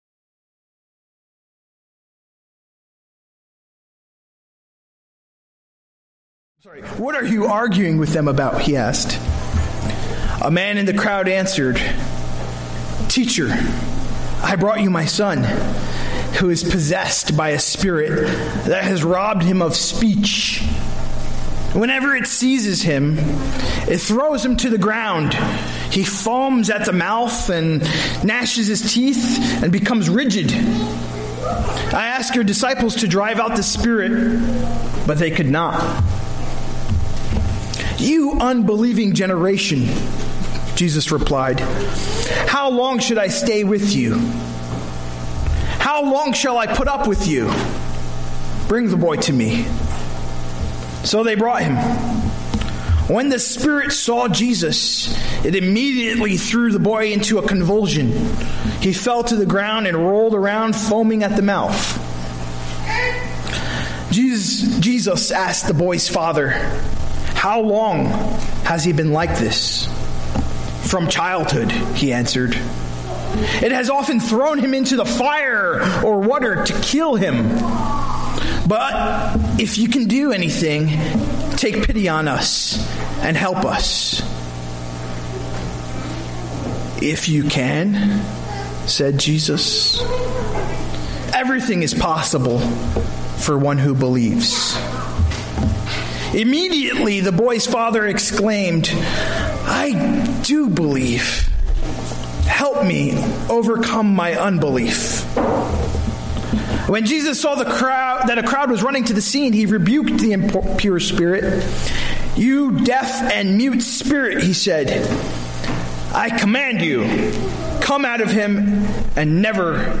A message from the series "Sunday Worship."
I Do Believe, Help Me Overcome My Unbelief - Mark 9:14-29 (Note: Due to technical issues the 1st part of the service wasn't recorded)